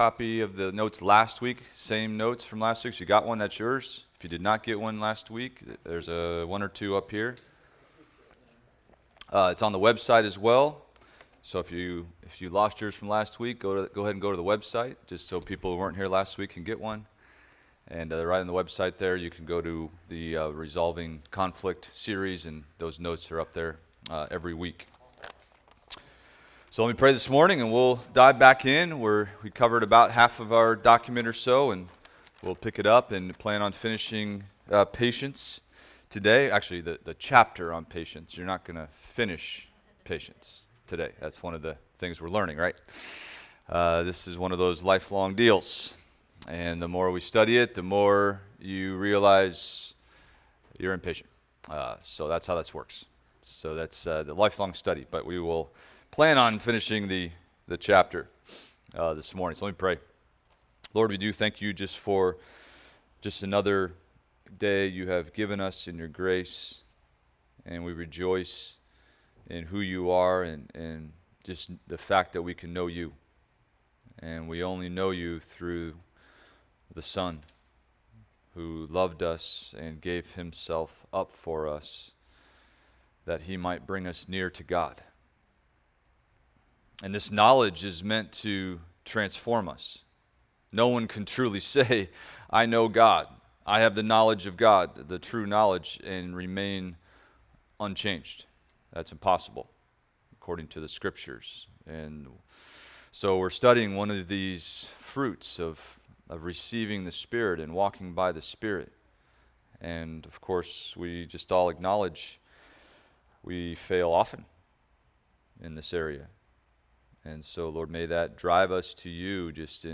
Adult Sunday School – Resolving Conflict – Week 4